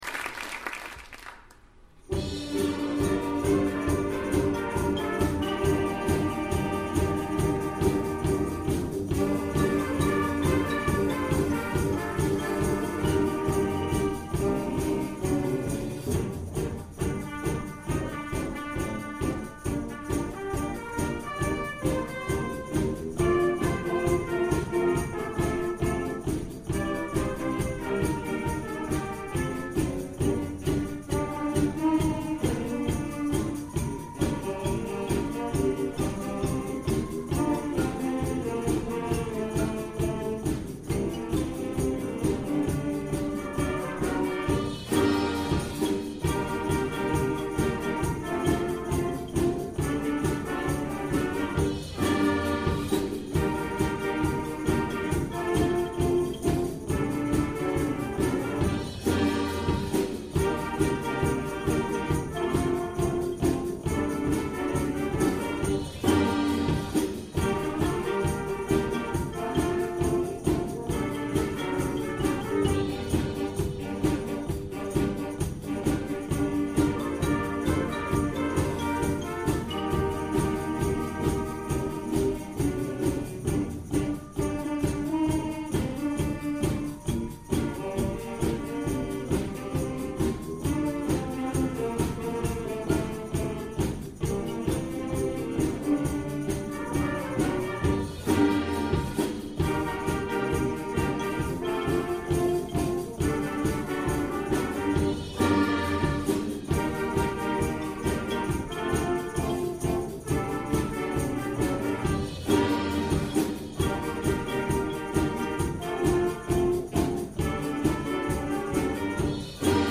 Junior Wind Band